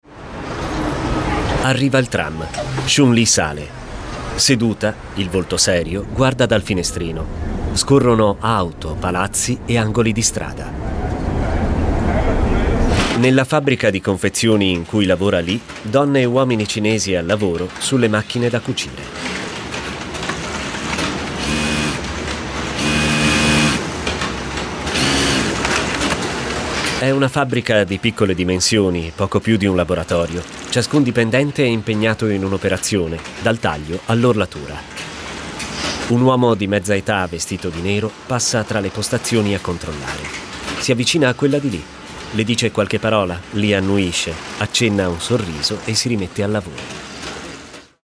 Audiodesc per non vedenti